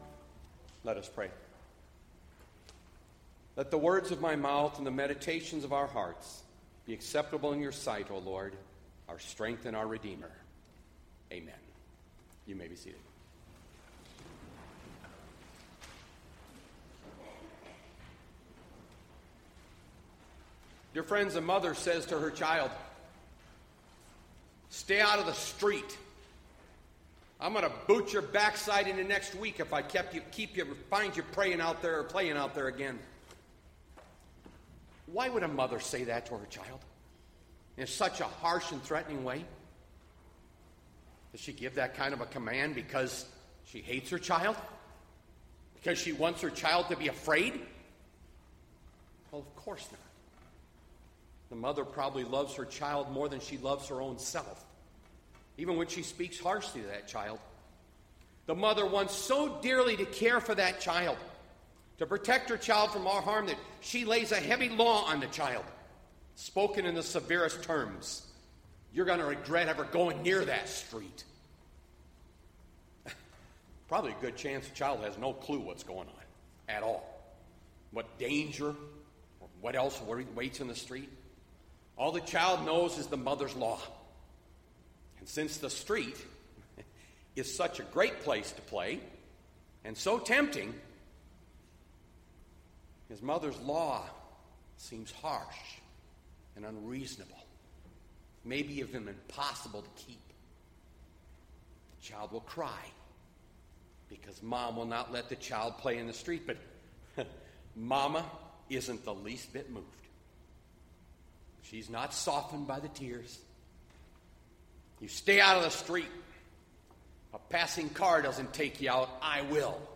Bethlehem Lutheran Church, Mason City, Iowa - Sermon Archive Sep 13, 2020